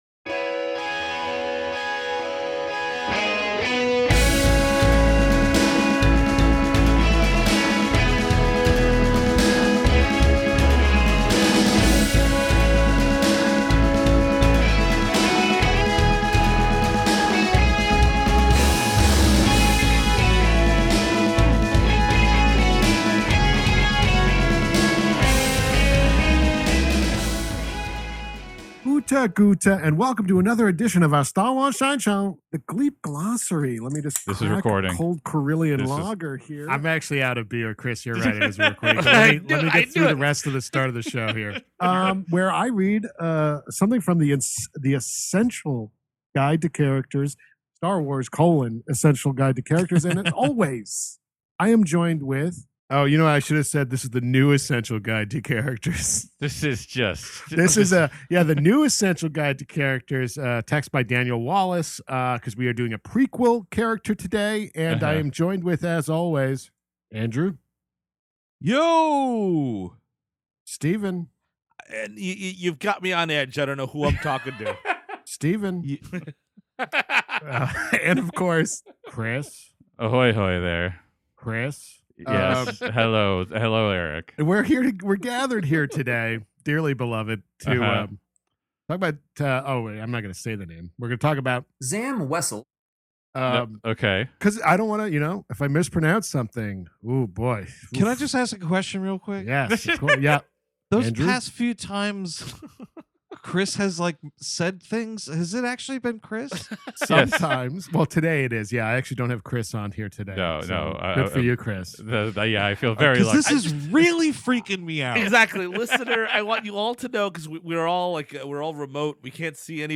PLUS: More DAVID-209 soundboard shenanigans!